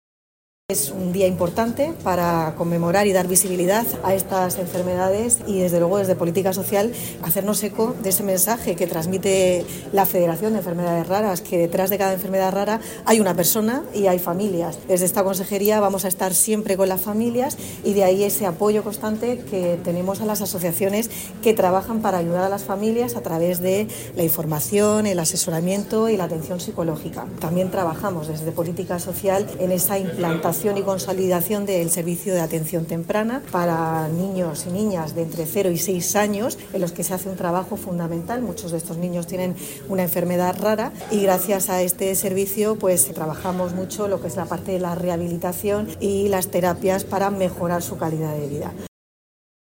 consejera de Política Social, Familias e Igualdad, Conchita Ruiz, con motivo de la celebración del Día Mundial de las Enfermedades Raras.
Los consejeros de Salud, Juan José Pedreño, y de Política Social, Familias e Igualdad, Conchita Ruiz, participaron hoy en el acto por el Día Mundial de las Enfermedades Raras